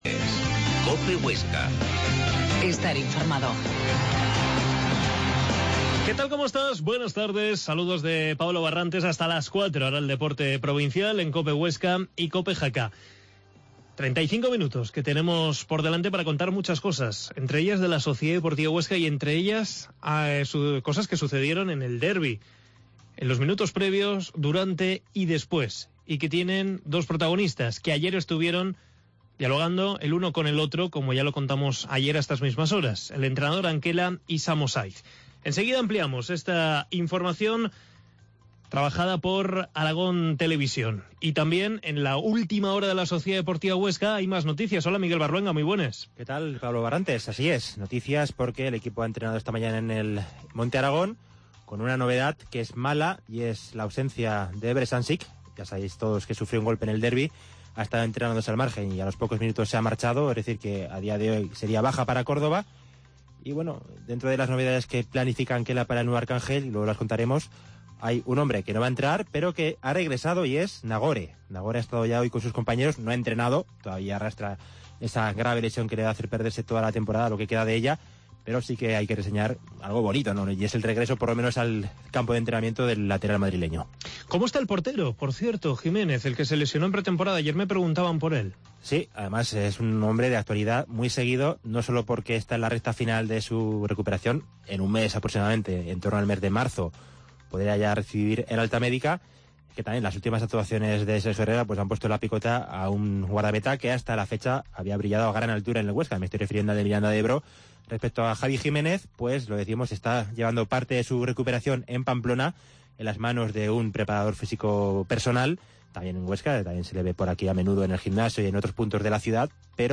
Desde Córdoba nos cuentan la última hora del rival. Entrevista